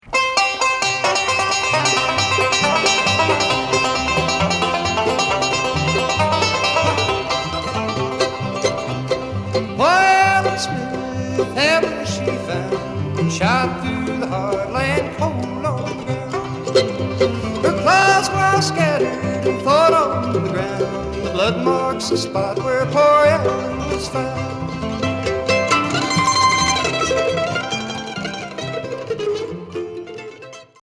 Bluegrass
mountain ballad